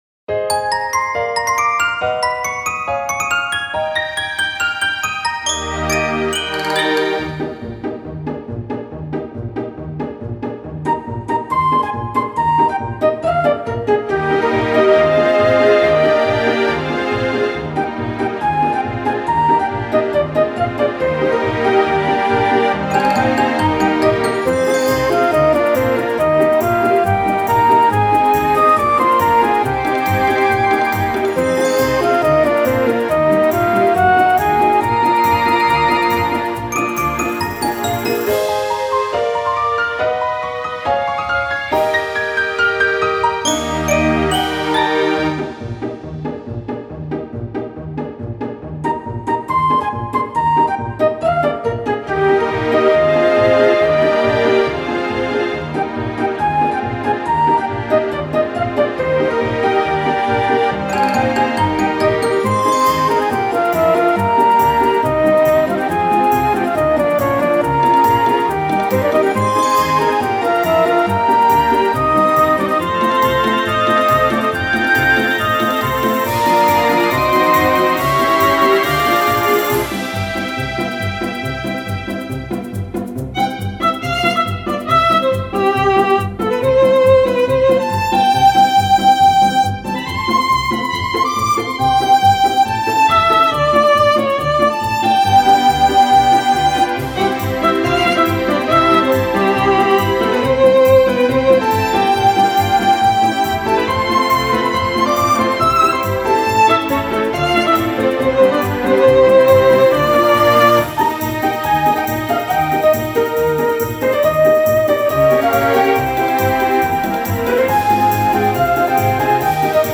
ogg(L) 街 欧風 軽快 オーケストラ
気分爽快で綺麗なオーケストラ。